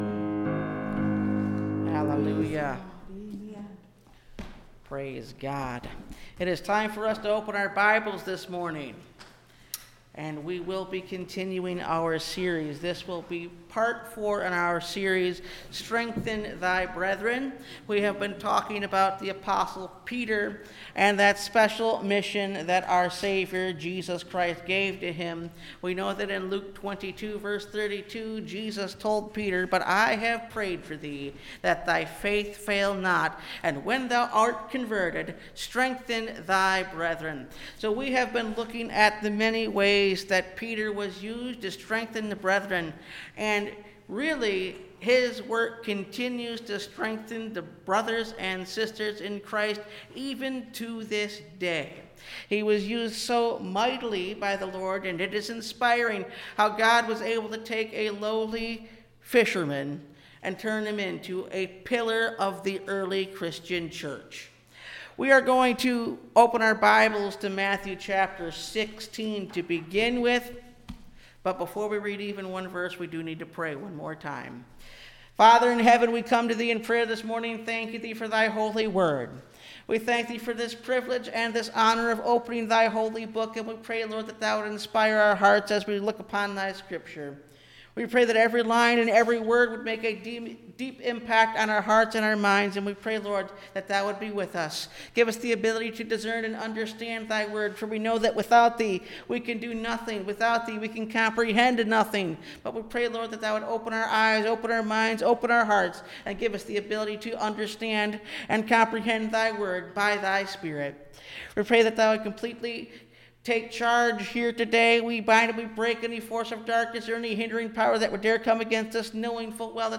Strengthen Thy Brethren – Part 4 (Message Audio) – Last Trumpet Ministries – Truth Tabernacle – Sermon Library
Service Type: Sunday Morning